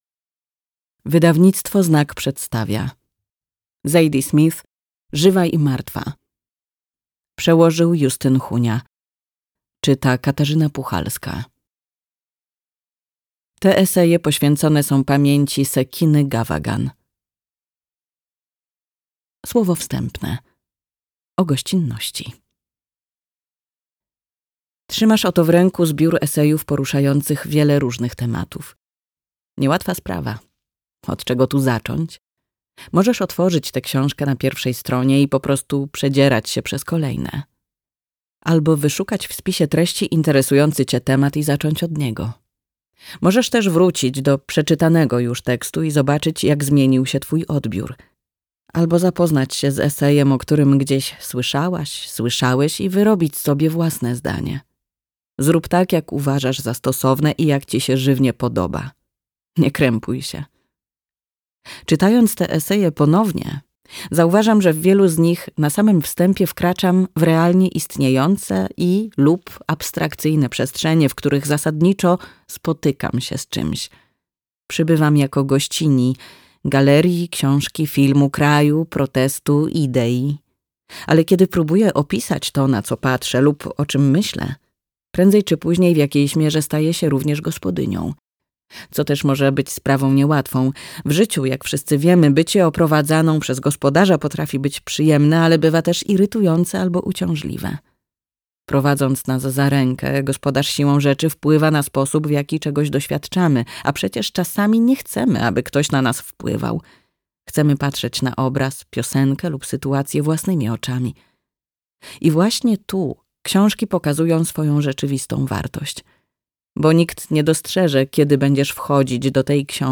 Żywa i martwa - Zadie Smith - audiobook + książka